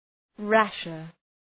{‘ræʃər}